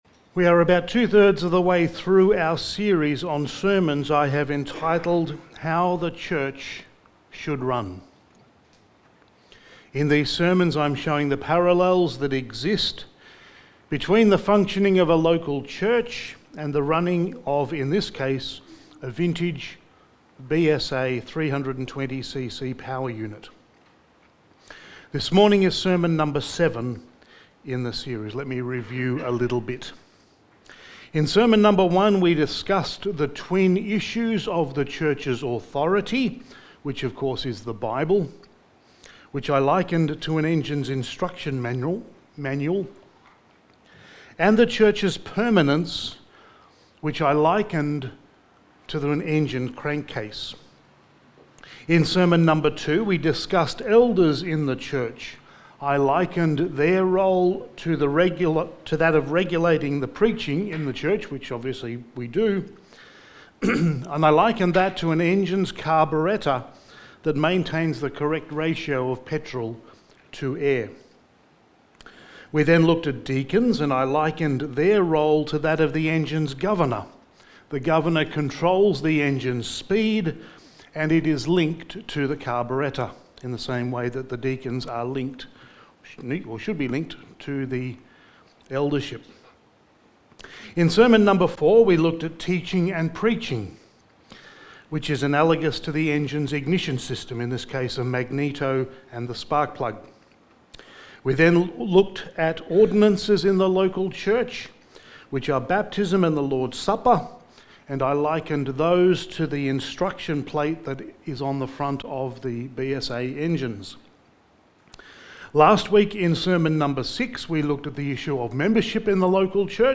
How the Church Should Run Series – Sermon 7: Fellowship in the Local Church
Service Type: Sunday Morning